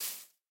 step_grass1.wav